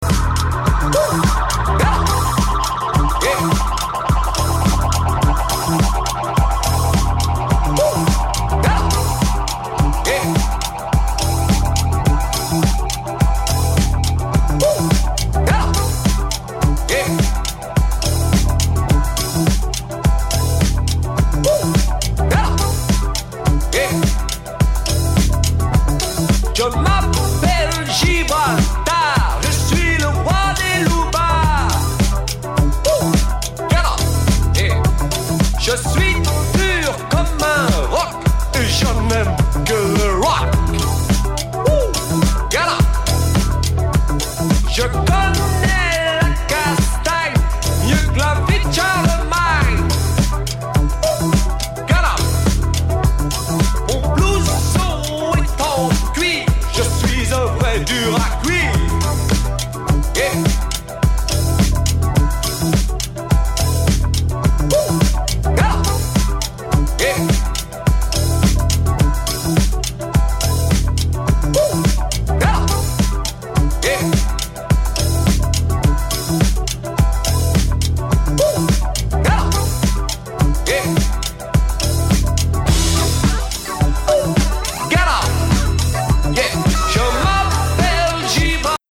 共にナイス・ブギーで踊れます！